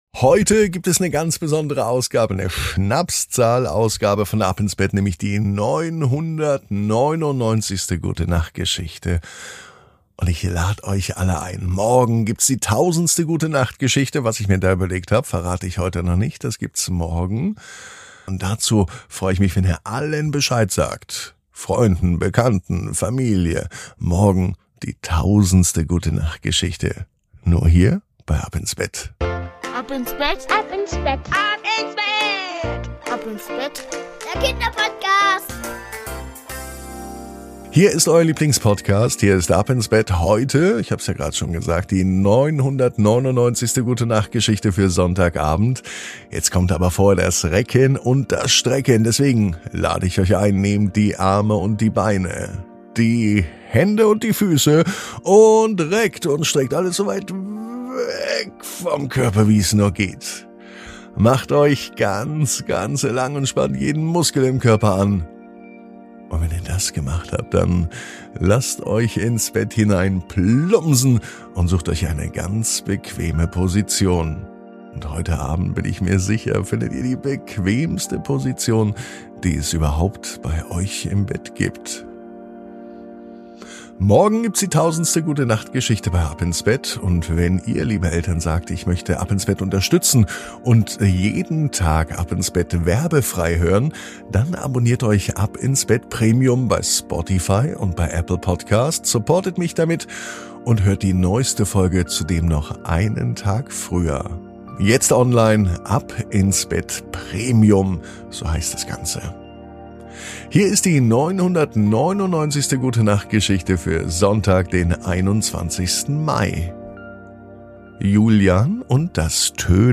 Die Gute Nacht Geschichte für Sonntag